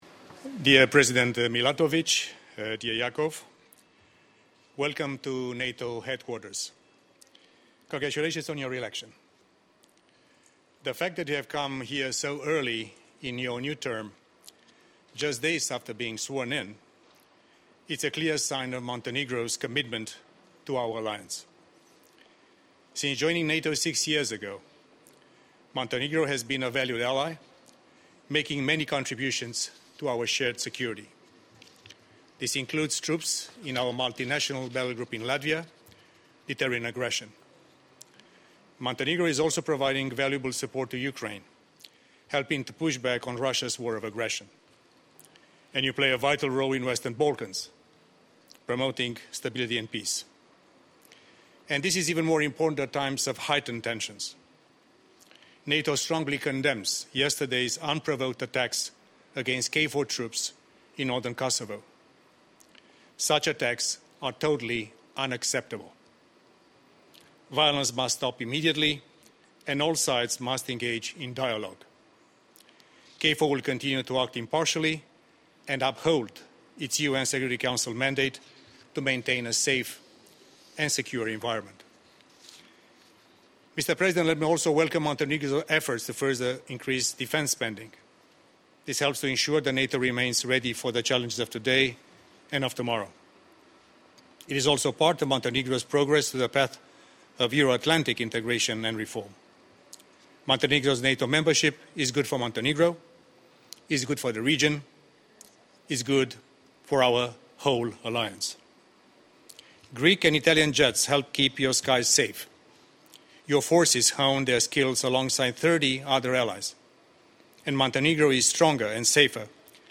Short statements by NATO Deputy Secretary General Mircea Geoană and the President of Montenegro, Jakov Milatović